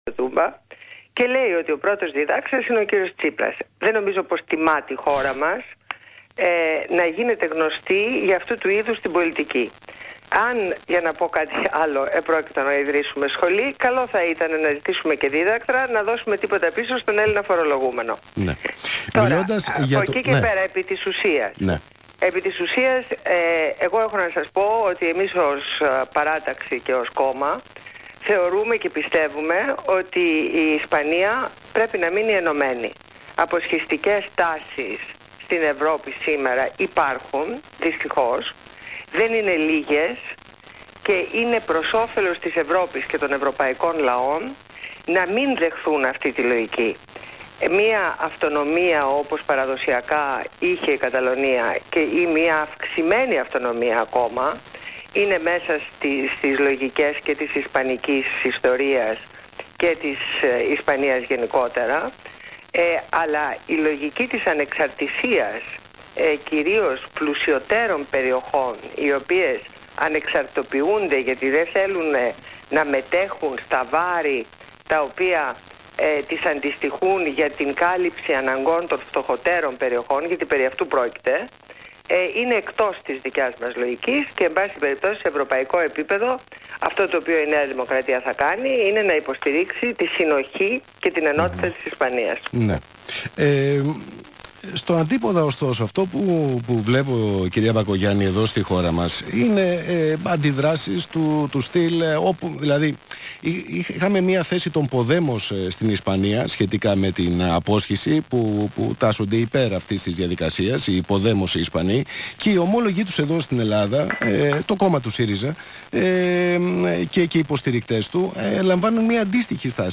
Συνέντευξη στο ραδιόφωνο του ΣΚΑΪ